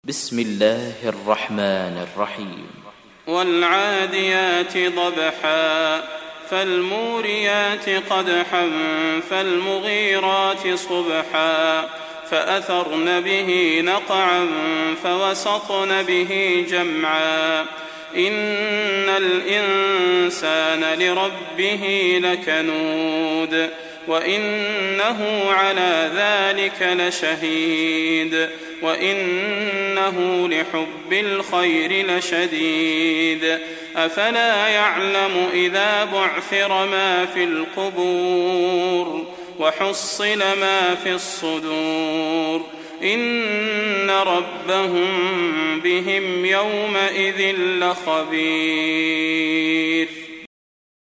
Surat Al Adiyat Download mp3 Salah Al Budair Riwayat Hafs dari Asim, Download Quran dan mendengarkan mp3 tautan langsung penuh